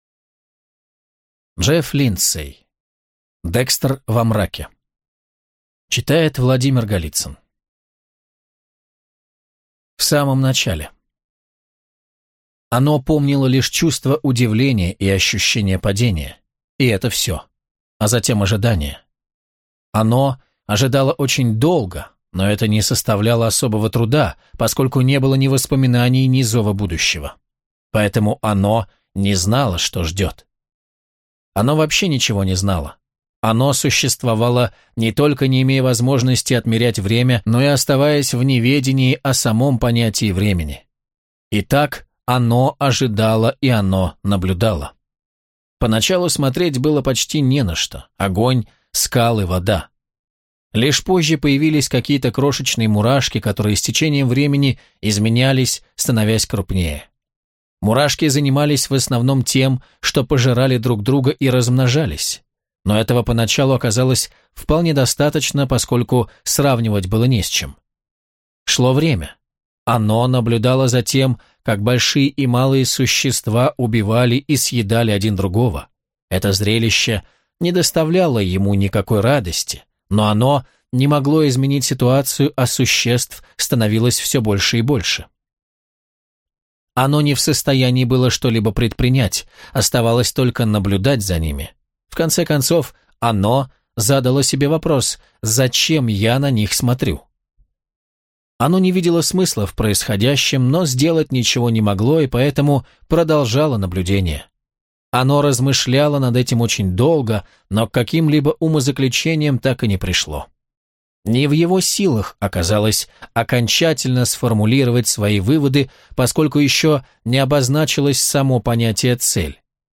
Аудиокнига Декстер во тьме | Библиотека аудиокниг